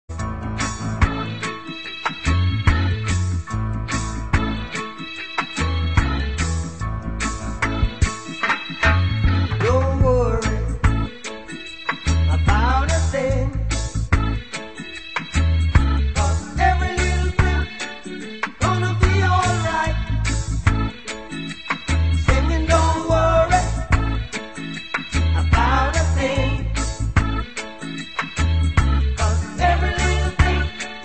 • Reggae Ringtones